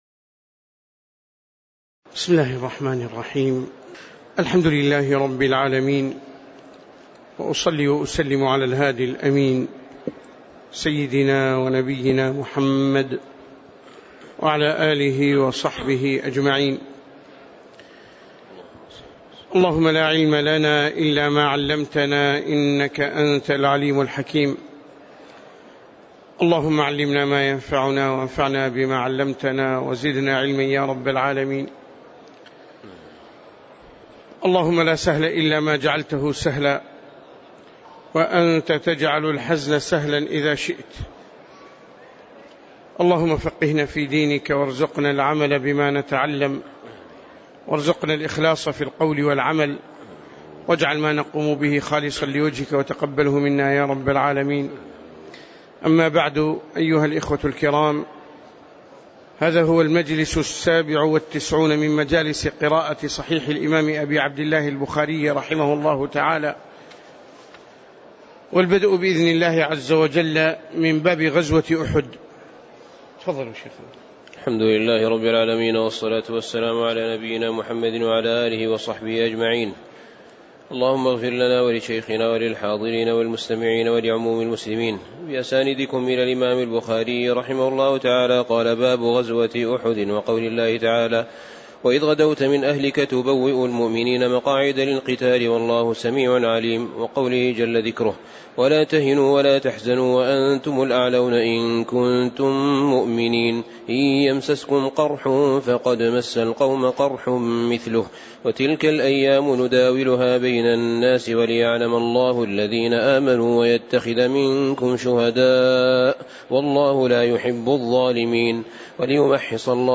تاريخ النشر ١٤ رجب ١٤٣٨ هـ المكان: المسجد النبوي الشيخ